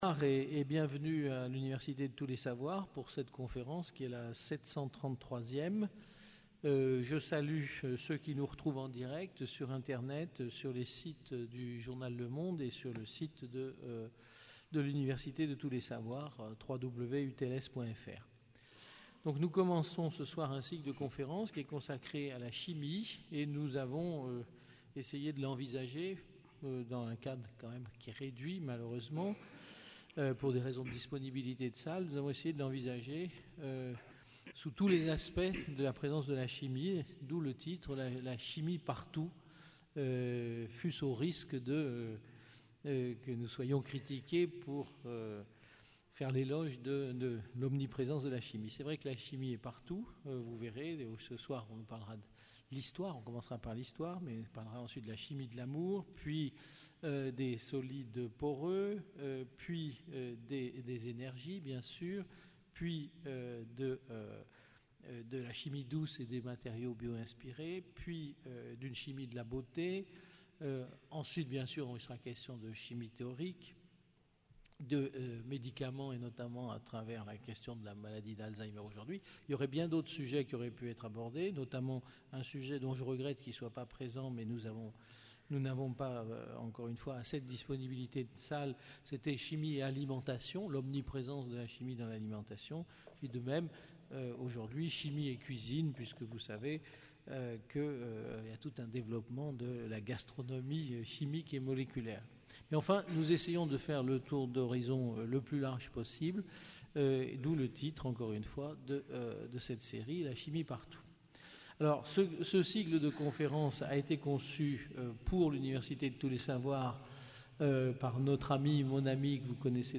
Une conférence UTLS du cycle : « La Chimie partout » du 21 au 29 mai 2011 à 18h30